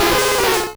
Cri de Lokhlass dans Pokémon Rouge et Bleu.